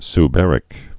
(s-bĕrĭk)